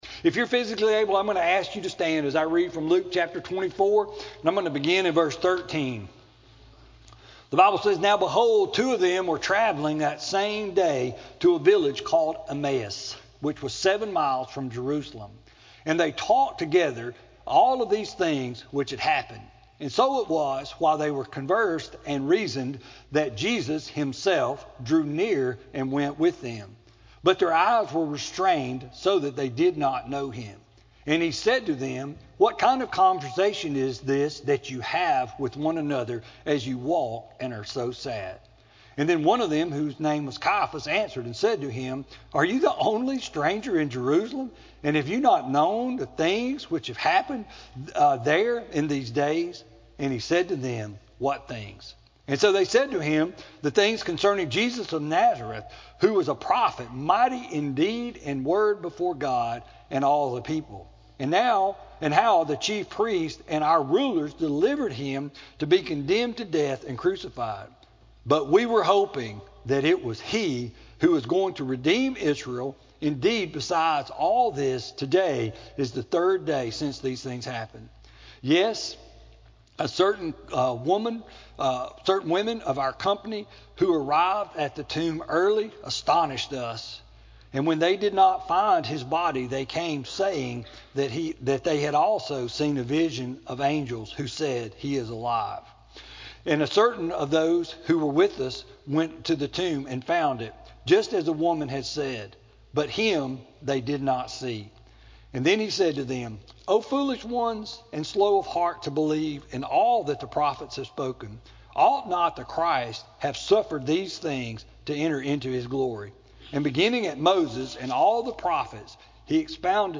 Sermon-4-28-19-CD.mp3